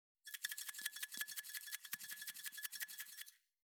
357七味を振る,一味,唐辛子,調味料,カシャカシャ,サラサラ,パラパラ,ジャラジャラ,サッサッ,ザッザッ,シャッシャッ,シュッ,パッ,サッ,
効果音厨房/台所/レストラン/kitchen